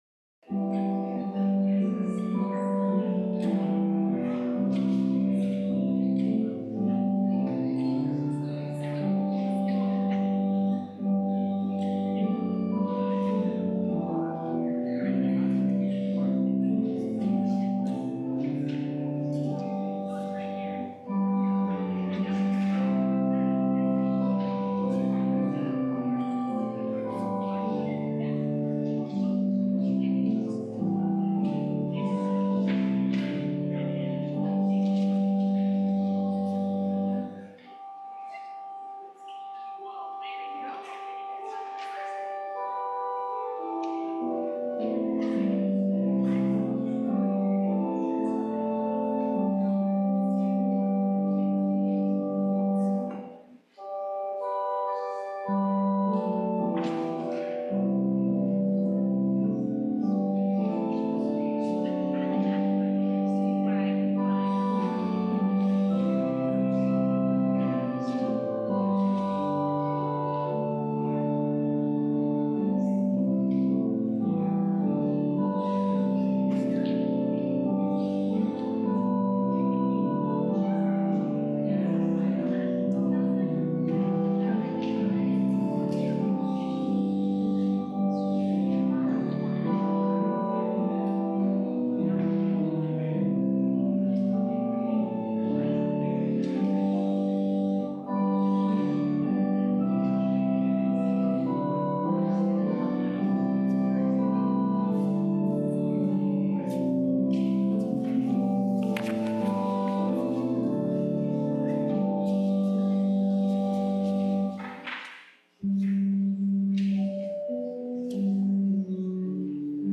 April 6 Worship with Communion | First Presbyterian Church, Fond du Lac